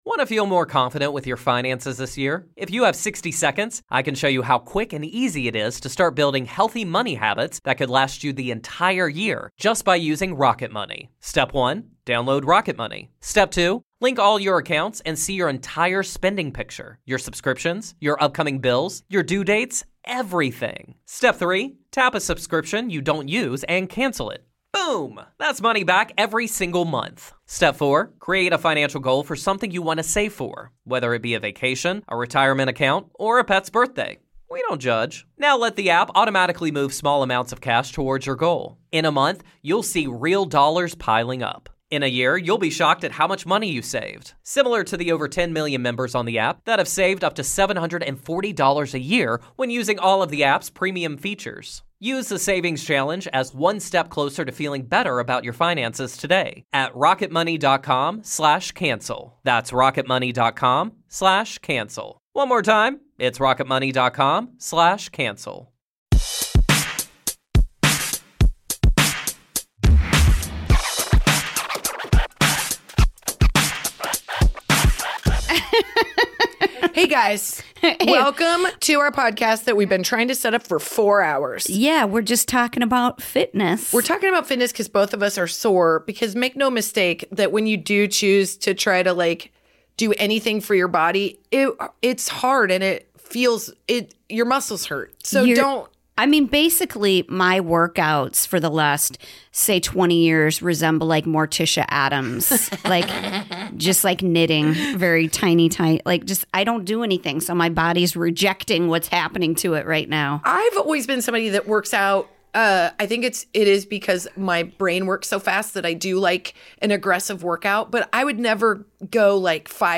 ABOUT US: This hilarious comedy podcast about motherhood is for moms by moms talking all about being a mom.
Female comedy duo